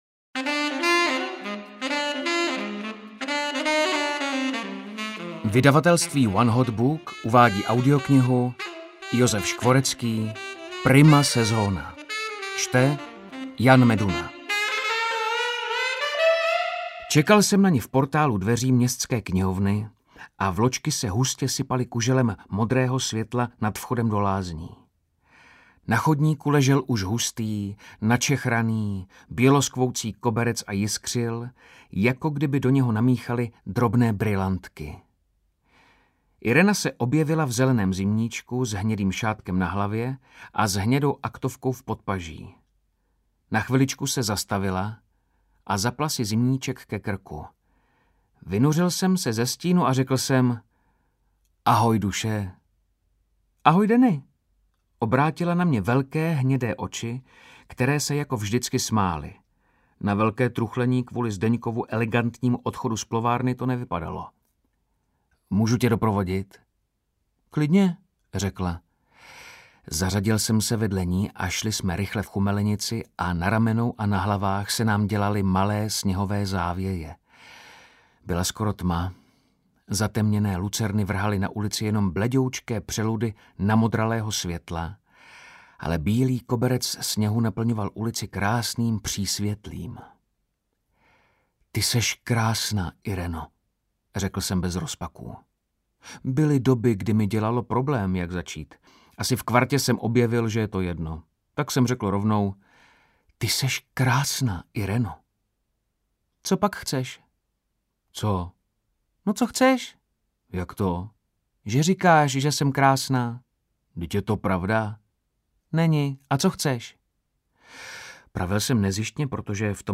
Audiobook